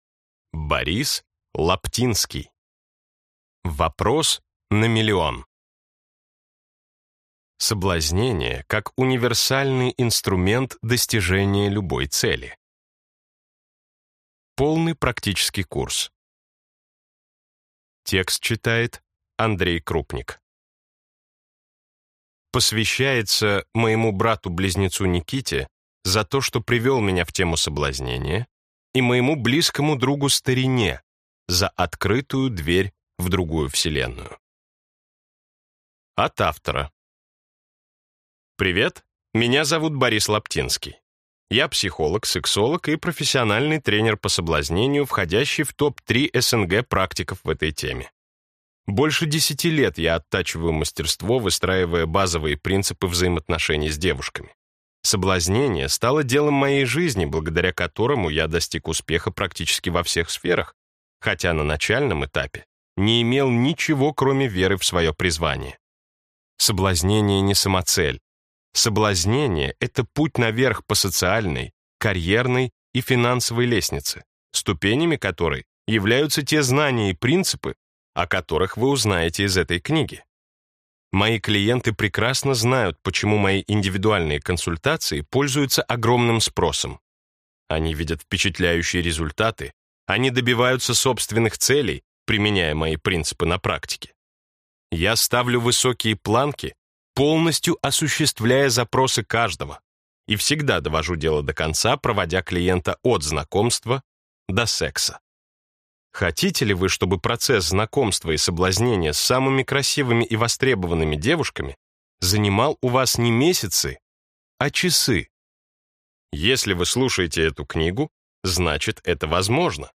Аудиокнига Вопрос на миллион | Библиотека аудиокниг